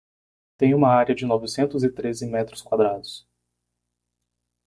Read more Num Noun Adj Frequency C1 Pronounced as (IPA) /ˈtɾe.zi/ Etymology Inherited from Latin trēdecim In summary Inherited from Old Galician-Portuguese treze, from Latin trēdecim.